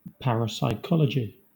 Ääntäminen
IPA : /ˌpɛɹəˌsaɪˈkɑlədʒi/